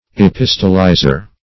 Epistolizer \E*pis"to*li`zer\, n. A writer of epistles.